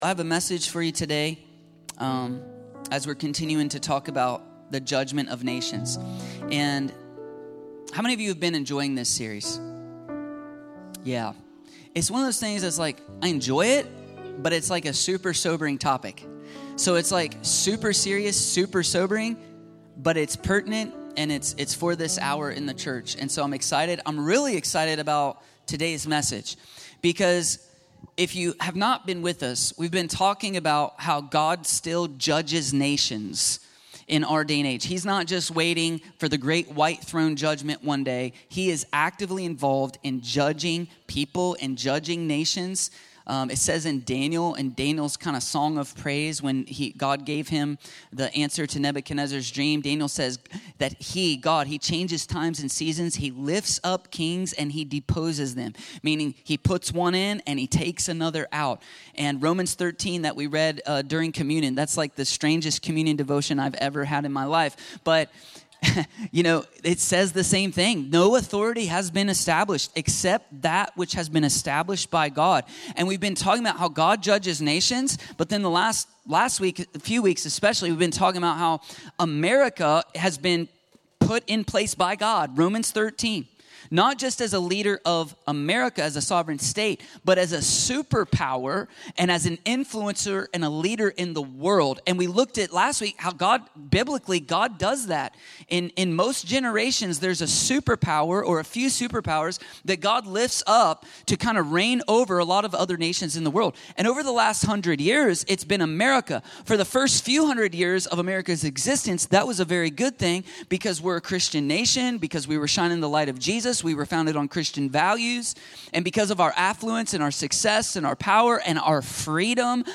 The Josiah Generation: Rise Up and Reform - The Judgement of Nations ~ Free People Church: AUDIO Sermons Podcast